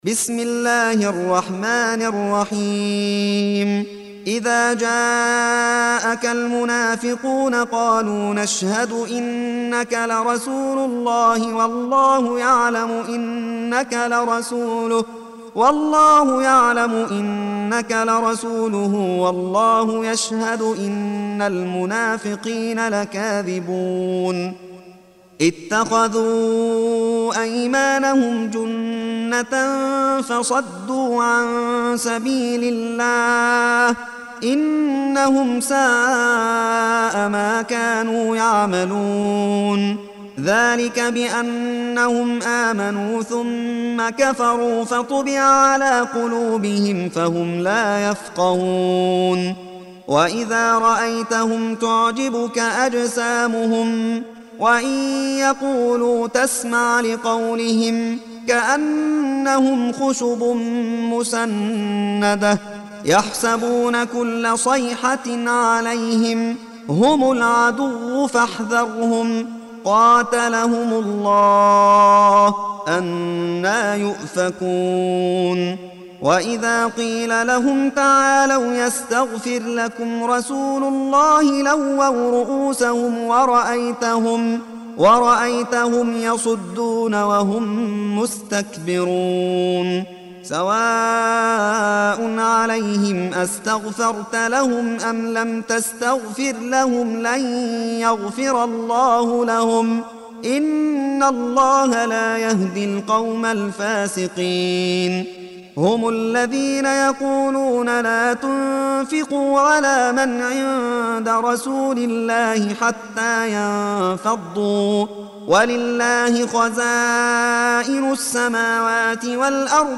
Surah Sequence تتابع السورة Download Surah حمّل السورة Reciting Murattalah Audio for 63. Surah Al-Munafiq�n سورة المنافقون N.B *Surah Includes Al-Basmalah Reciters Sequents تتابع التلاوات Reciters Repeats تكرار التلاوات